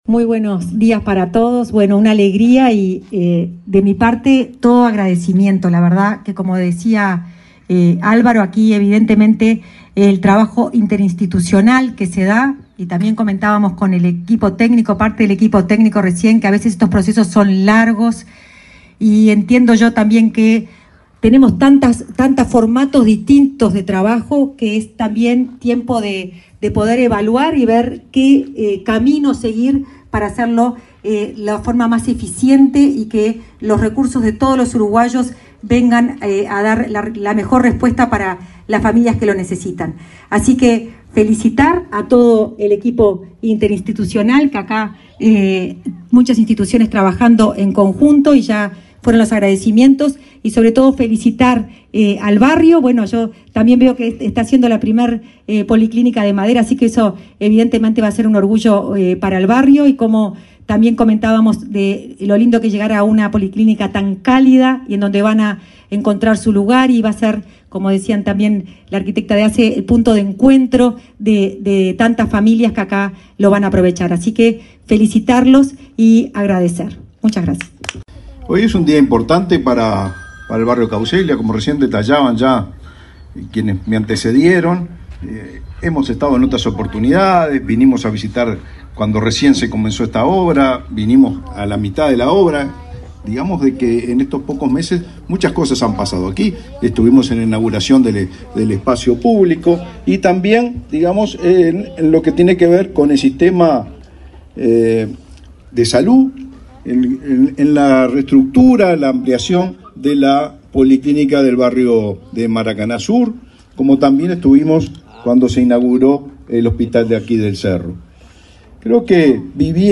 Palabras de autoridades en inauguración de policlínica en Montevideo